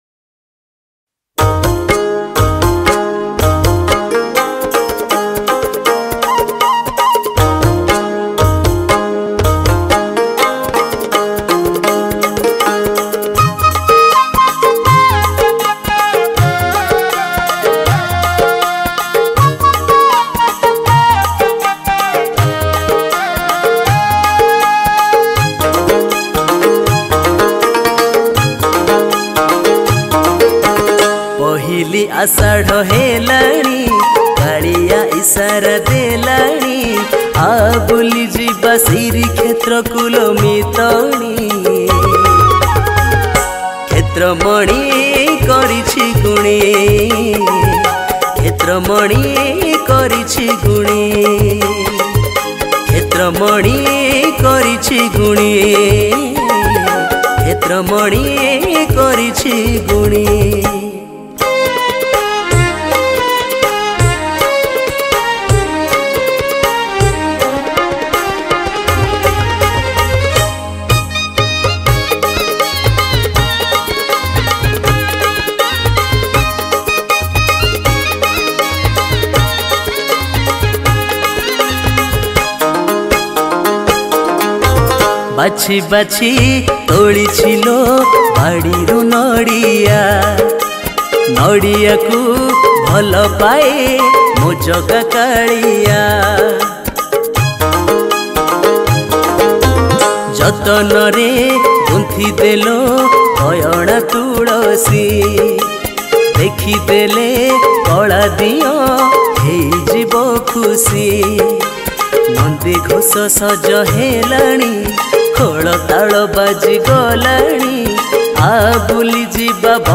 Ratha Yatra Odia Bhajan 2022 Songs Download